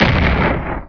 resist_rocket.wav